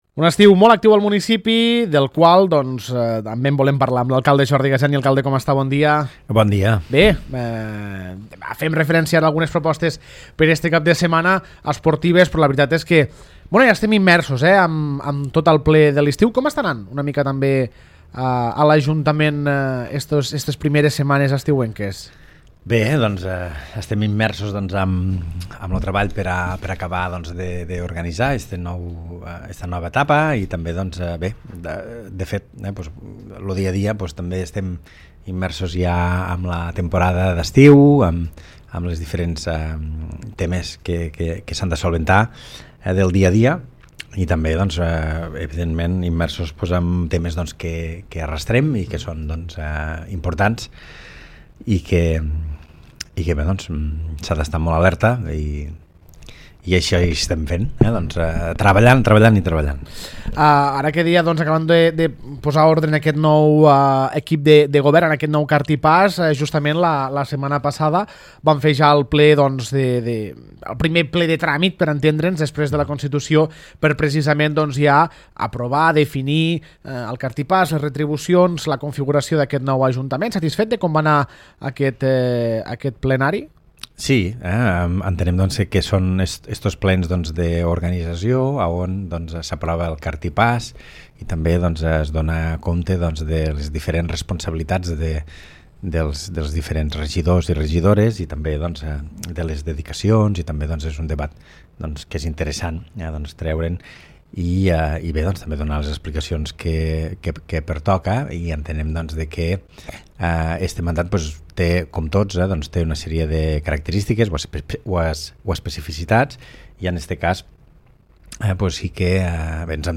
Jordi Gaseni, alcalde de l’Ametlla de Mar